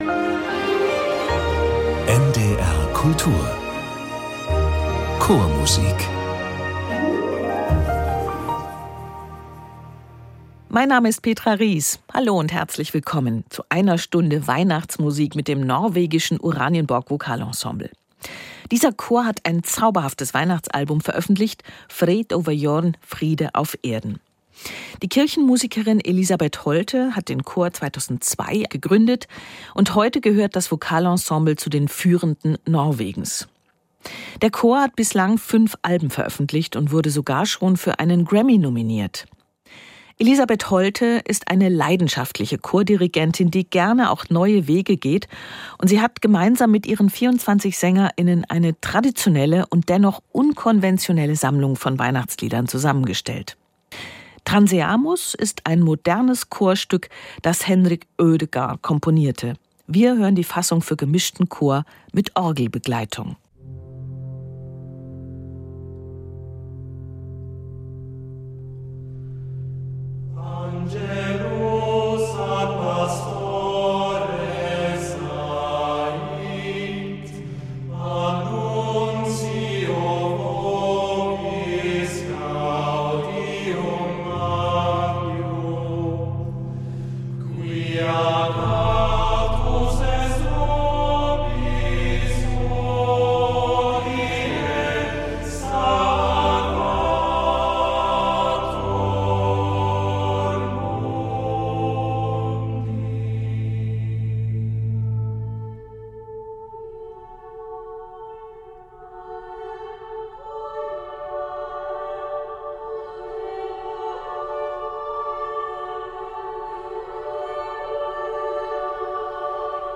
Chormusik